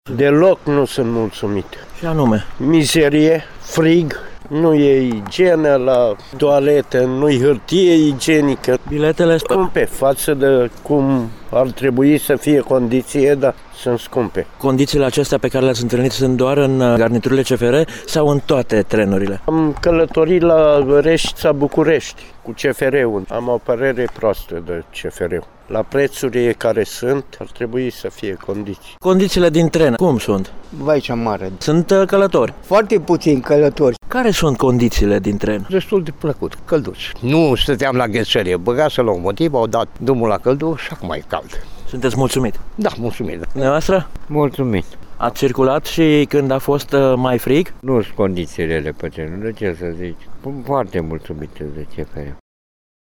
Cu bagajele îngheţate bocnă pasagerii se revoltă. Părerile călătorilor despre condiţiile din garniturile CFR sunt însă împărţite: